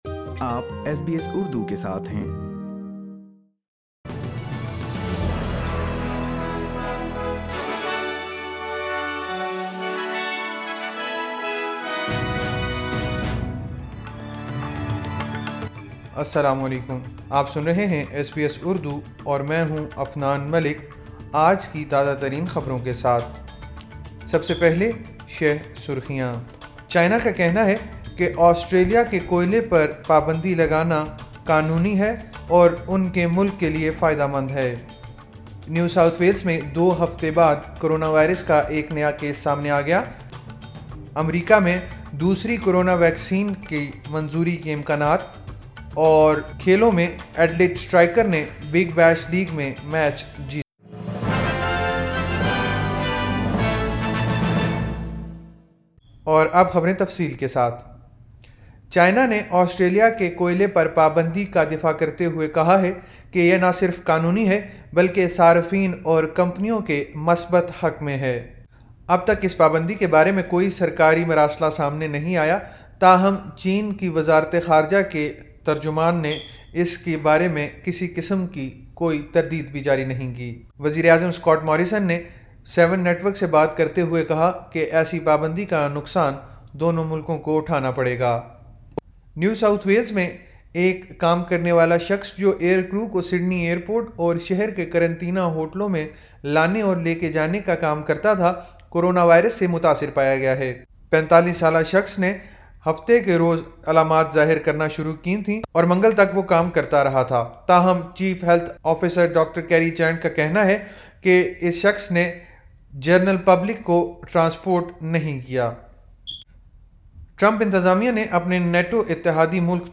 ایس بی ایس اردو خبریں 16 دسمبر 2020